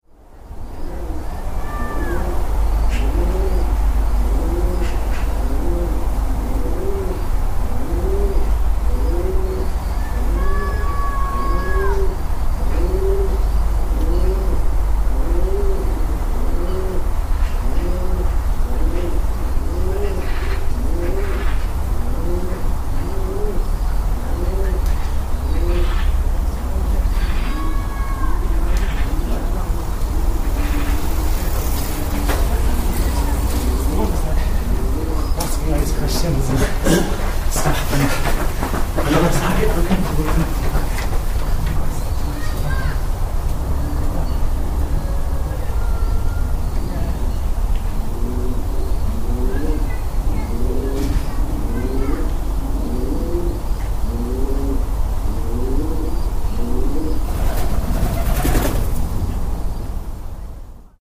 Under Grandpont Bridge, 07.09.13
Under the bridge in Grandpont, Oxford. Pigeons nesting, children playing, birdsong, natural reverb and, er, joggers.